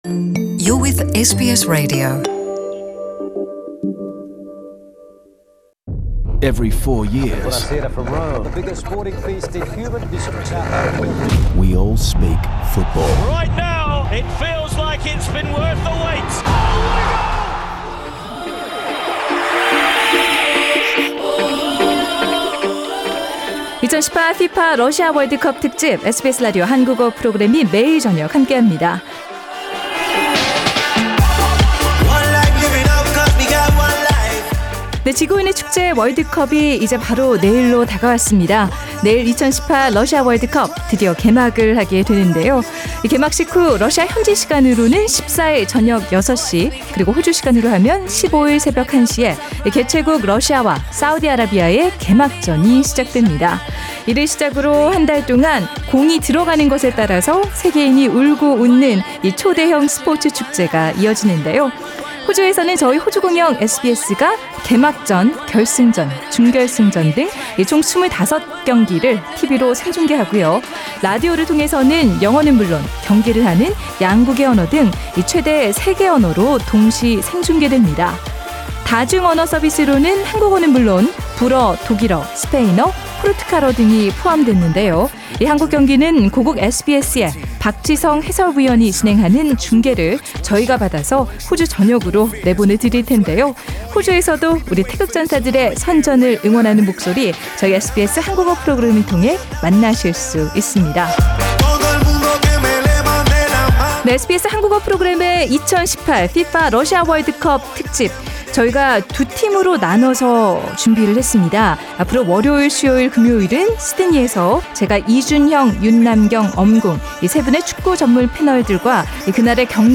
SBS Korean Program’s the 2018 FIFA World Cup Russia Special Series launch with a panel of five soccer experts.